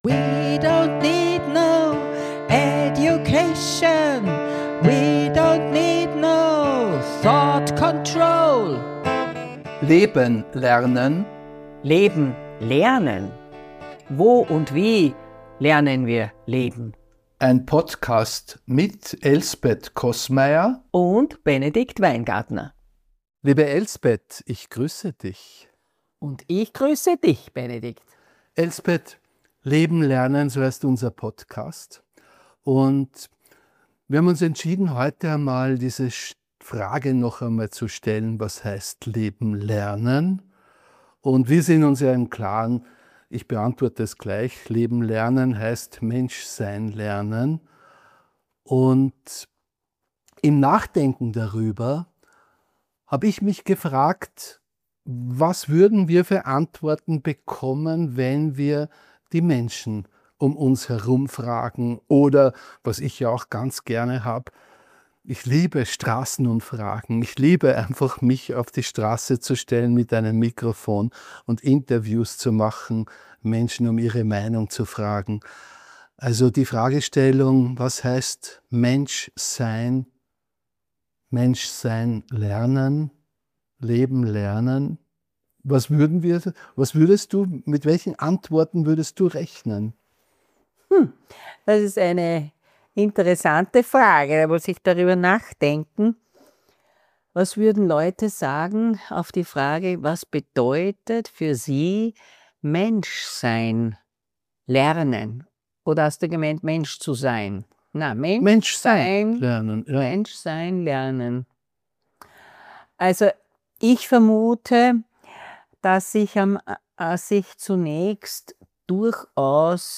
Mit ihren unterschiedlichen Zugängen führen sie kritisch und mit utopischen Gedanken im Hinterkopf einen Dialog.